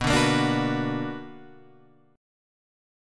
B Minor Major 7th